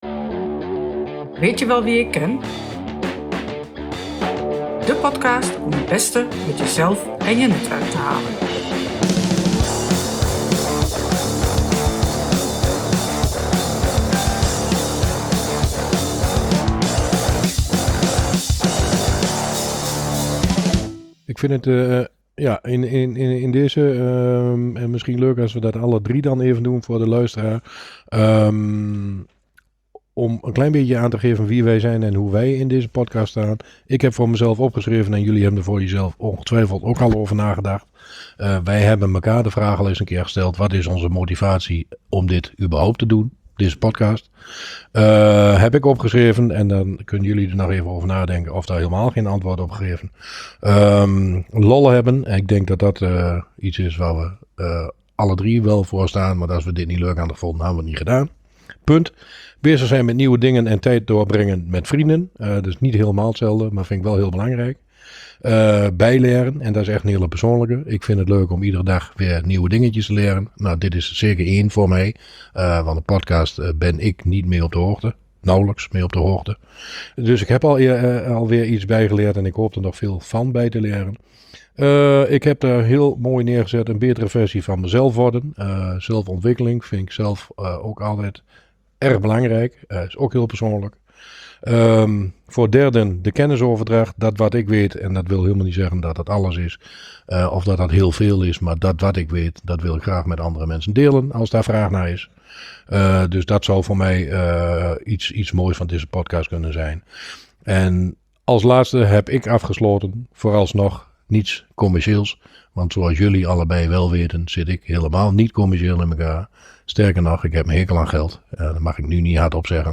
Weet je wel wie ik ken is een podcast voor ondernemers, door ondernemers, en is er op gericht om luisteraars te inspireren en op deze manier het beste uit jezelf en je netwerk te halen. Netwerken is dan ook de rode draad in alle episodes en dit topic laten wij dan ook in ieder interview, bij iedere gast terug komen. De Hosts zijn drie zeer verschillende ondernemers, die door zeer uiteenlopende gasten een zo breed mogelijke kijk willen geven in de wereld van de ondernemerschap.